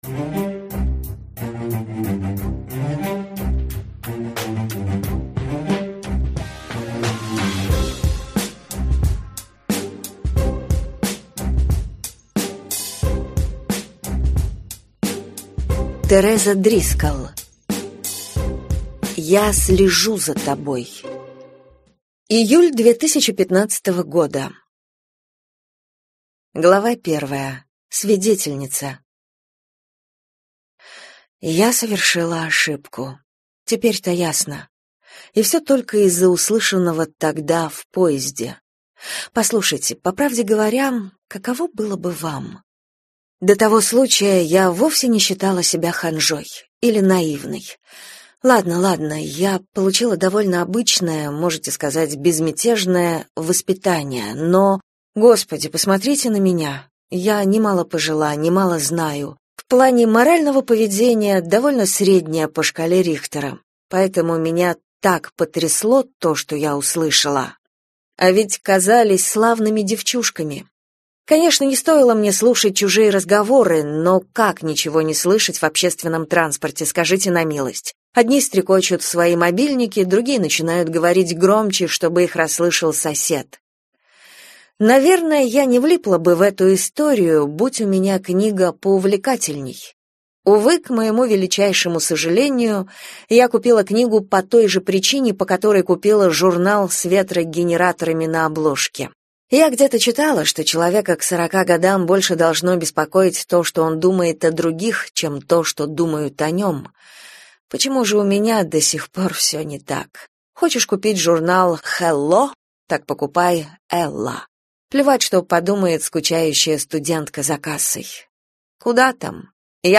Аудиокнига Я слежу за тобой | Библиотека аудиокниг
Прослушать и бесплатно скачать фрагмент аудиокниги